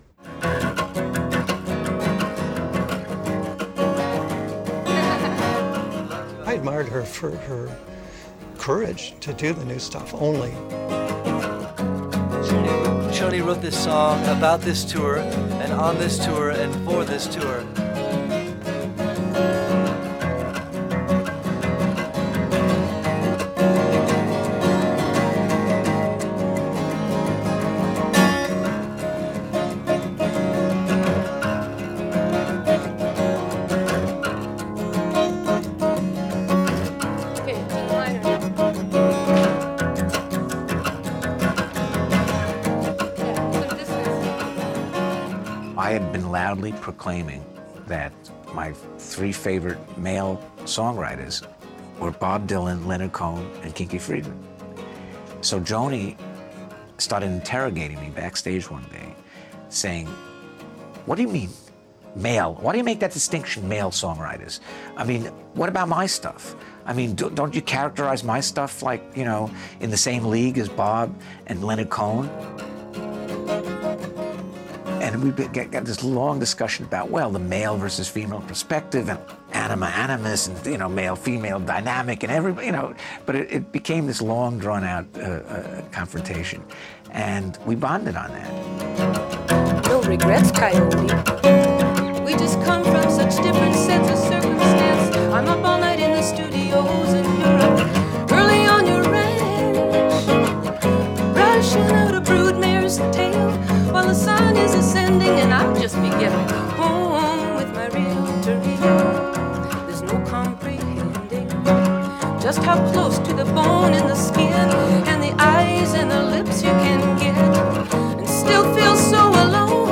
Een prachtig nummer, virtuoos gespeeld, mooi gezongen.